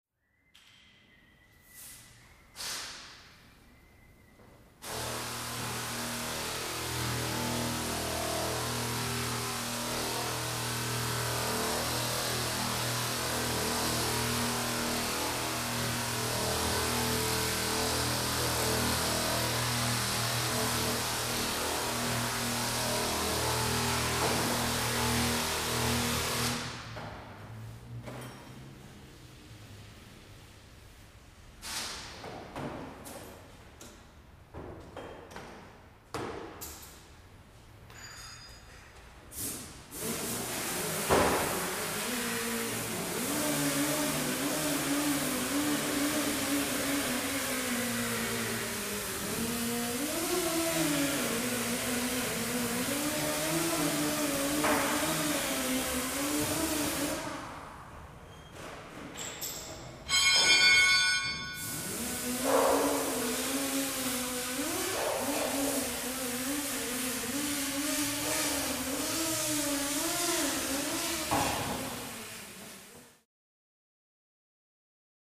Auto Shop
Automobile Body Shop Ambience, W Pneumatic Tools And Hammering Dents.